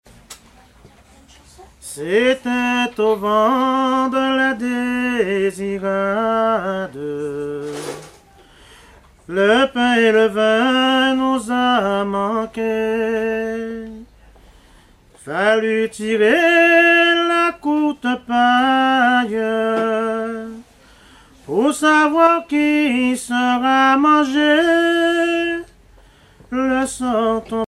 Genre laisse
chansons anciennes recueillies en Guadeloupe
Pièce musicale inédite